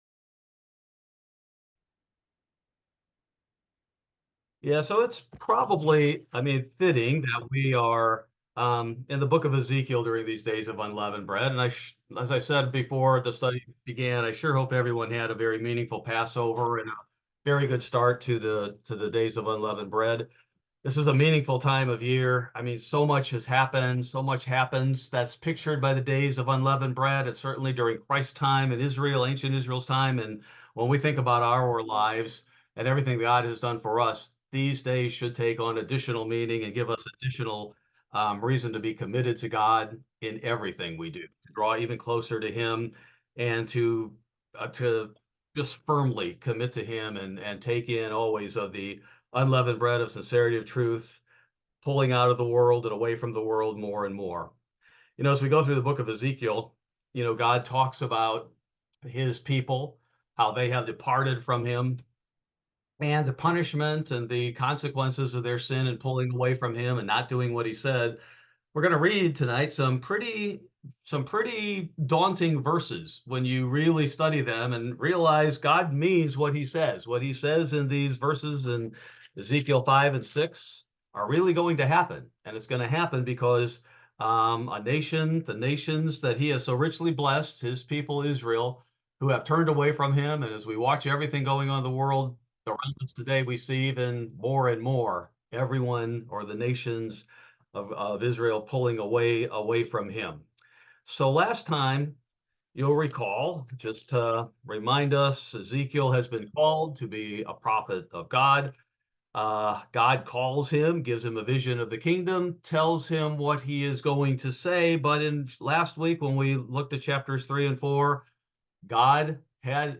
Bible Study: April 24, 2024